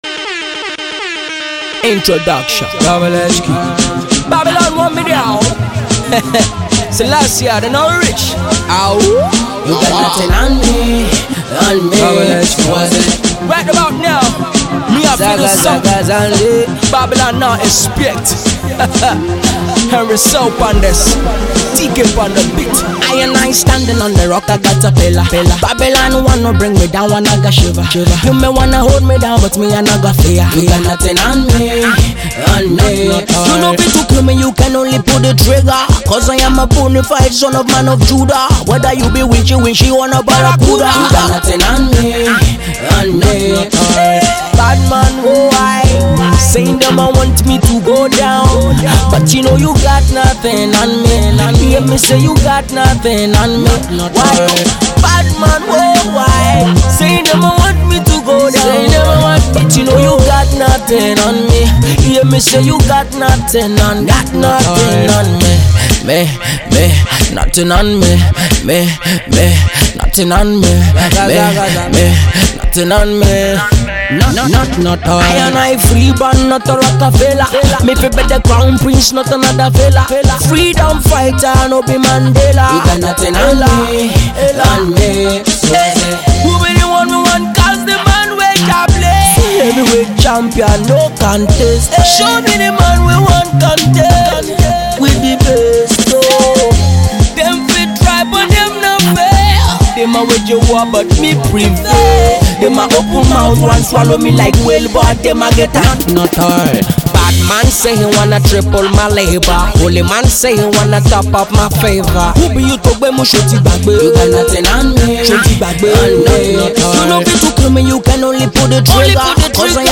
When the bass hits
a ride through the dancehall/ragga jungle